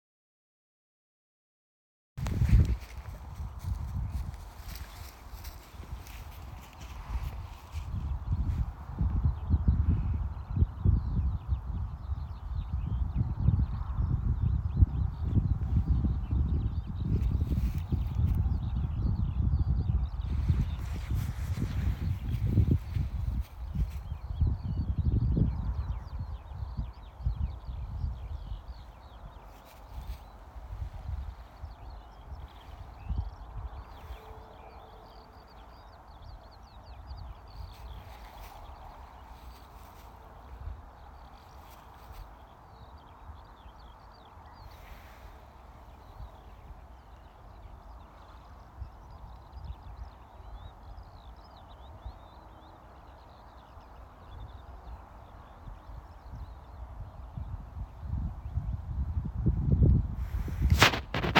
Birds -> Larks ->
Skylark, Alauda arvensis
StatusVoice, calls heard